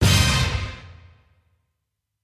На этой странице собраны звуки викторин — от классических сигналов правильного ответа до зажигательных фанфар.
Звуковое оповещение завершения викторины